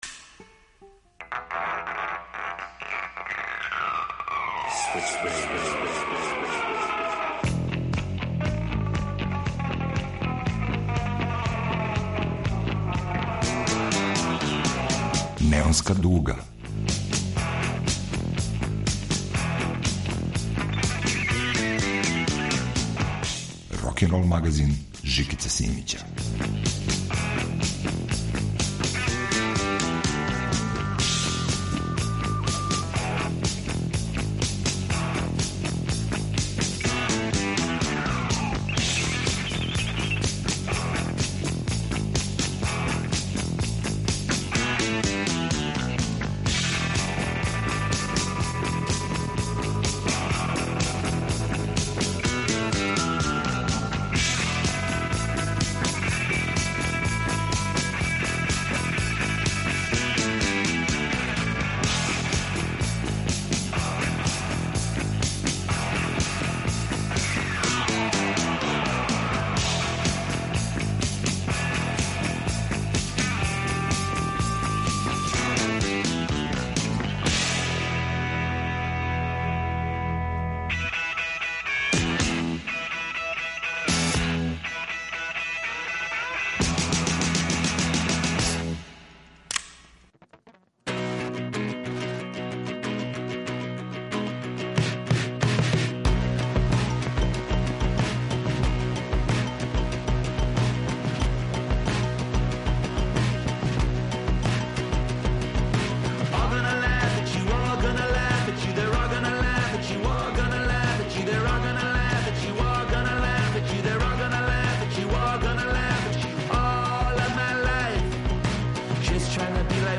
Очекује нас лепо путовање у буги ритму кроз егзотични рок универзум. Преко 20 песама најновије продукције је на репертоару.
Рокенрол као музички скор за живот на дивљој страни.